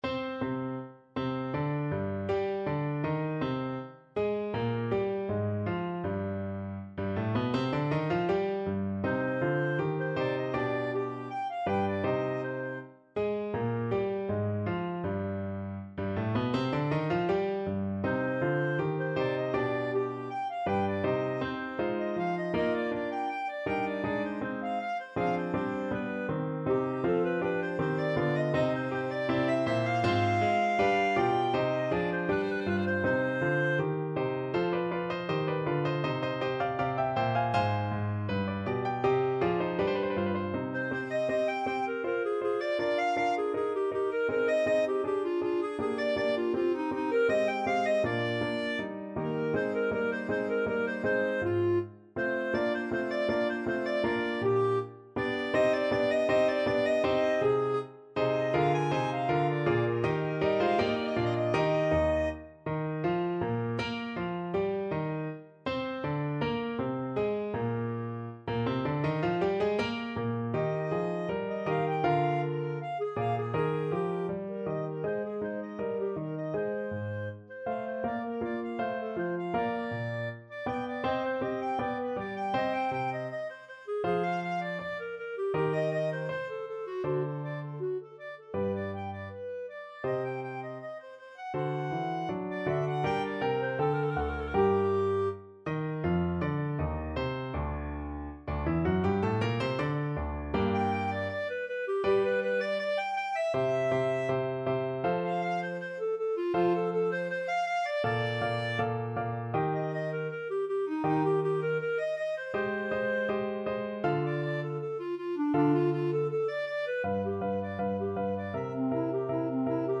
Clarinet
Andante e spiccato
4/4 (View more 4/4 Music)
C minor (Sounding Pitch) D minor (Clarinet in Bb) (View more C minor Music for Clarinet )
Clarinet Sheet Music
marcello_concerto_1_CL.mp3